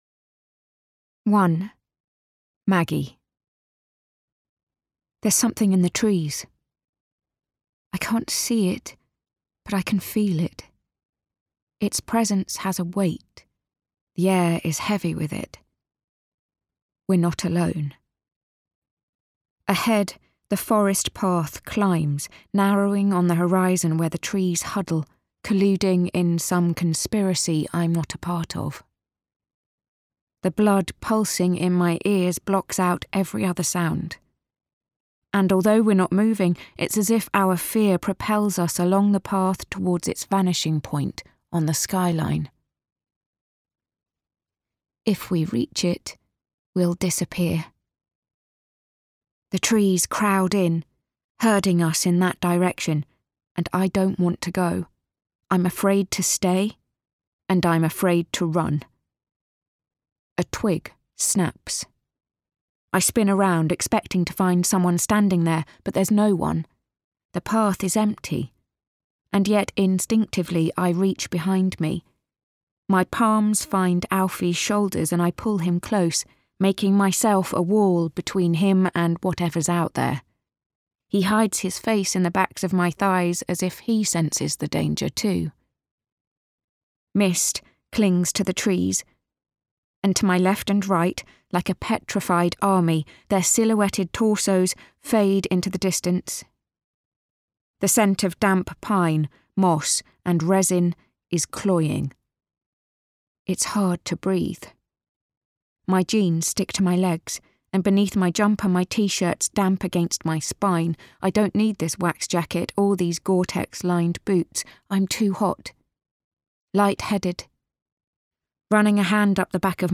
STONE the DEAD Crows (Audiobook)
Performed by Joanne Froggatt (Downton Abbey) and Louise Brealey (Sherlock)